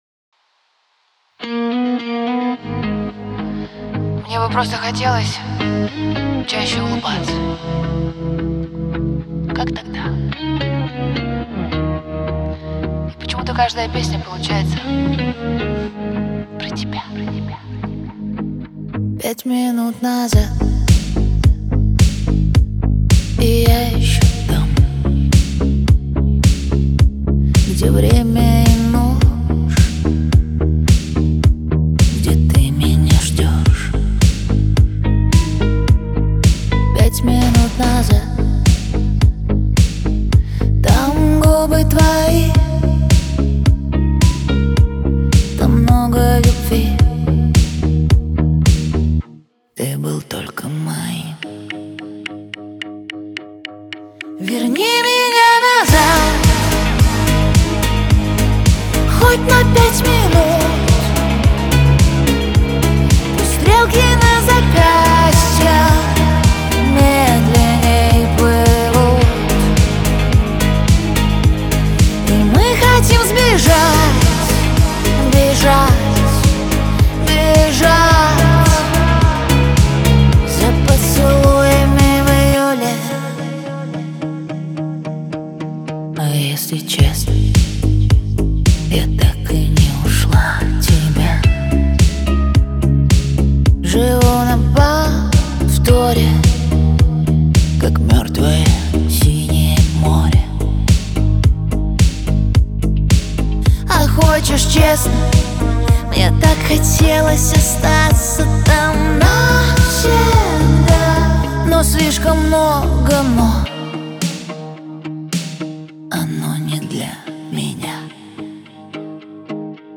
Качество: 320 kbps, stereo
Поп музыка, Русские треки